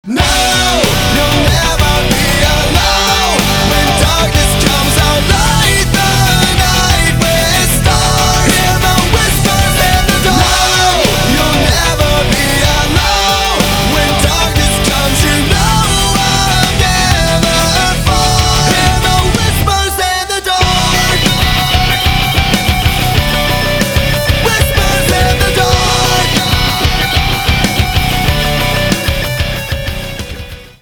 • Качество: 320, Stereo
мужской вокал
громкие
Alternative Metal
nu metal
бодрые
христианский рок
Industrial metal